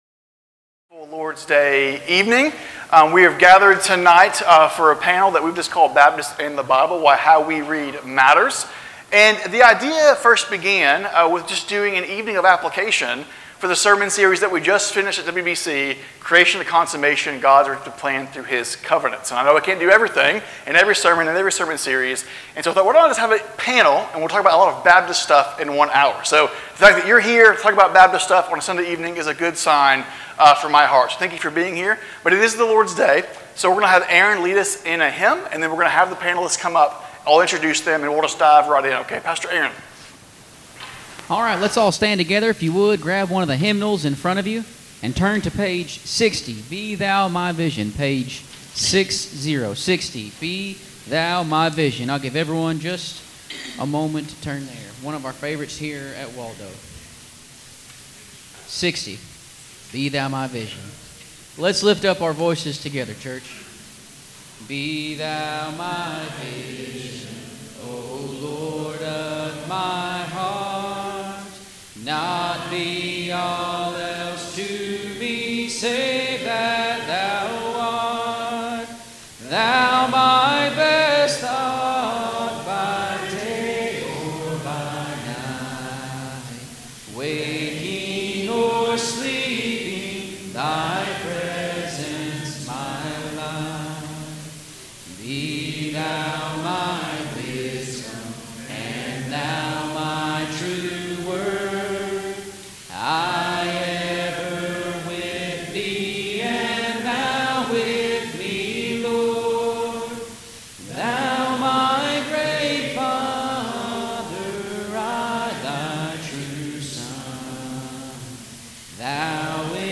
Sermons | Waldo Baptist Church
Panel Discussion